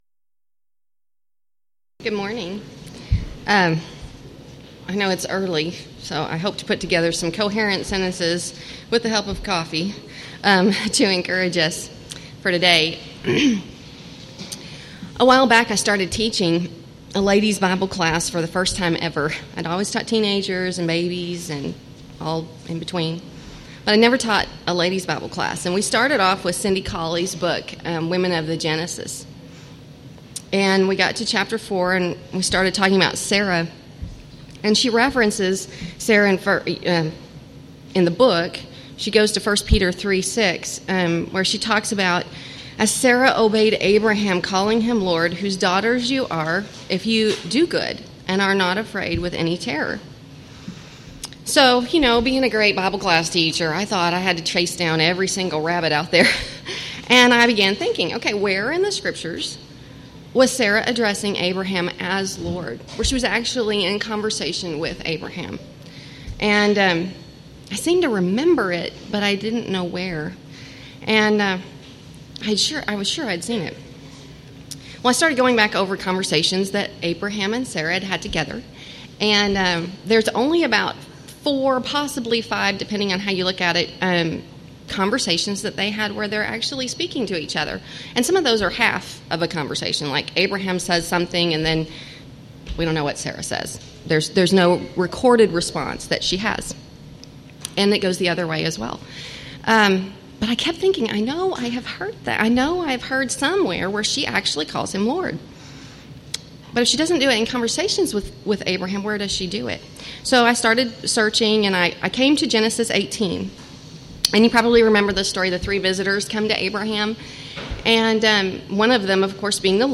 Title: Devotional
Event: 1st Annual TLC Retreat Theme/Title: Philippians